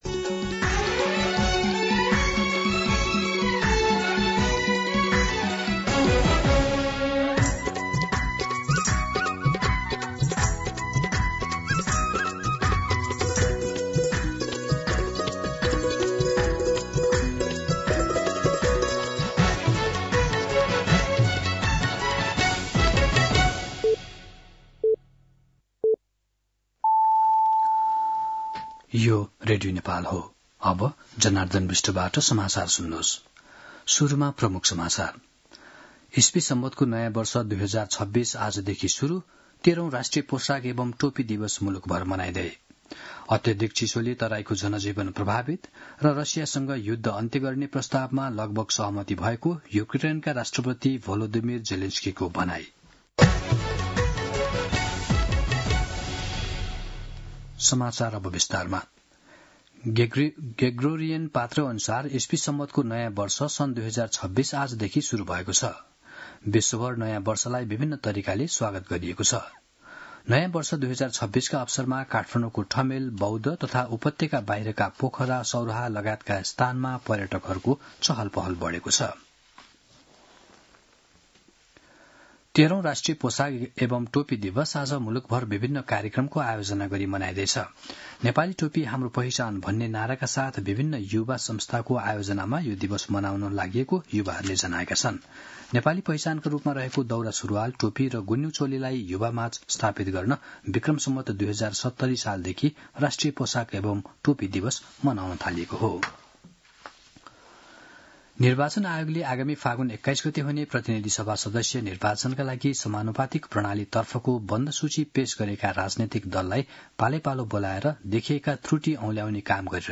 दिउँसो ३ बजेको नेपाली समाचार : १७ पुष , २०८२